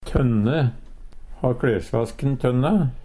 tønne - Numedalsmål (en-US)
DIALEKTORDET Enkelt ord tønne tørke Infinitiv Presens Preteritum Perfektum tønne tønna tønna tønna Eksempel på bruk Har klesvasken tønna?